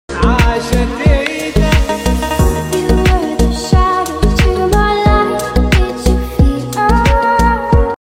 Roaring the throttle for Small sound effects free download